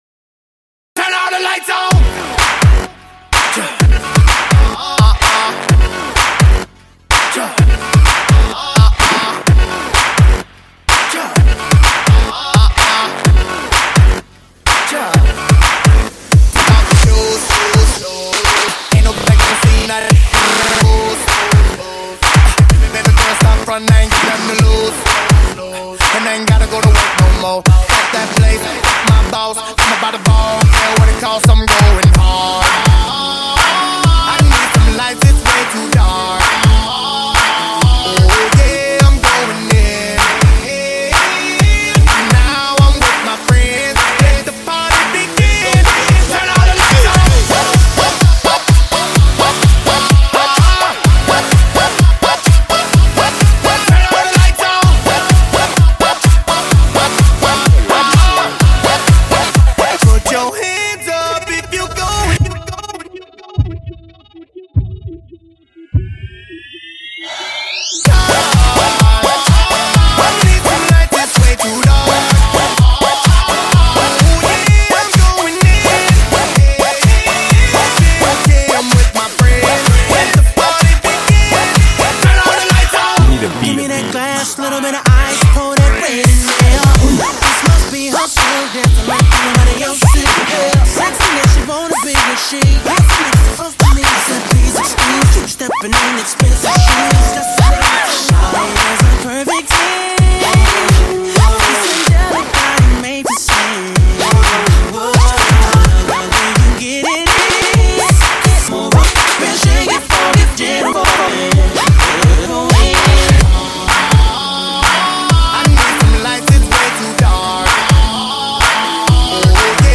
Latest DJ-Mixes